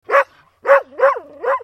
Dog 5 Sound Effect Download: Instant Soundboard Button